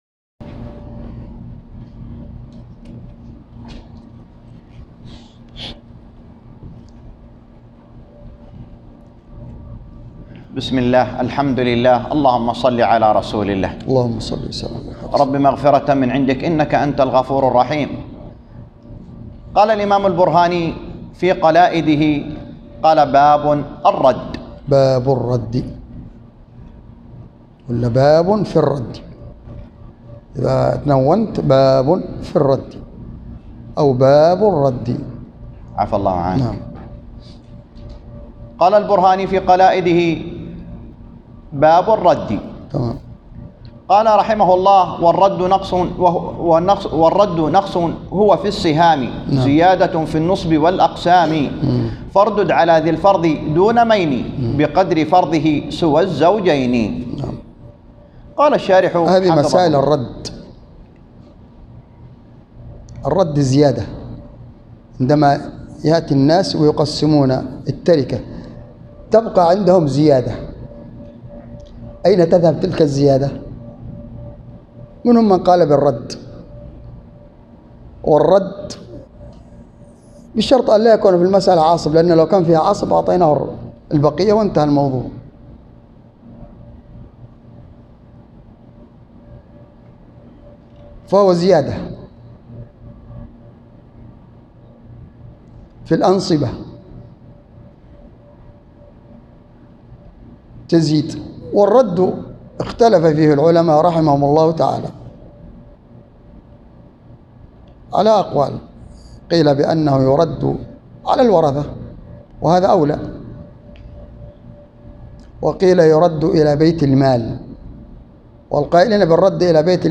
الدرس الثامن و الاربعون - شرح المنظومة البرهانية في الفرائض _ 48